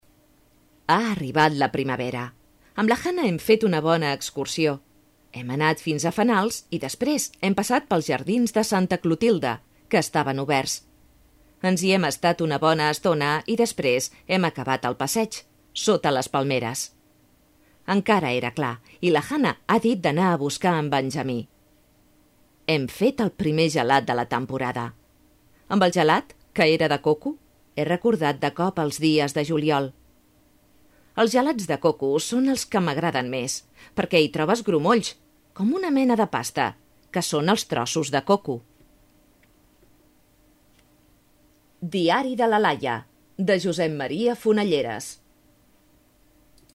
Voz media y joven.
kastilisch
Sprechprobe: Sonstiges (Muttersprache):